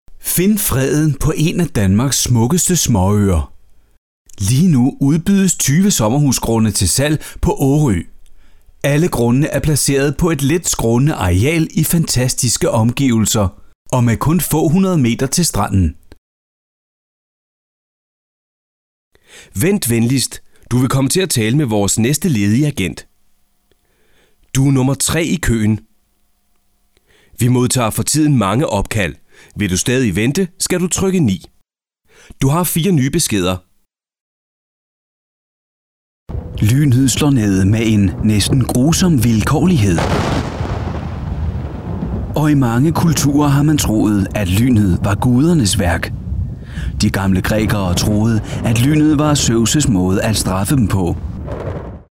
I am a male speaker living in Denmark.
Sprechprobe: Werbung (Muttersprache):
Danish voice over talent.